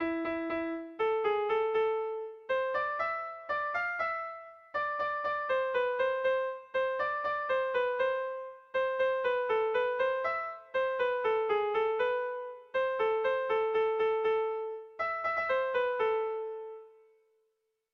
Melodías de bertsos - Ver ficha   Más información sobre esta sección
Irrizkoa
Zortziko txikia (hg) / Lau puntuko txikia (ip)
ABDE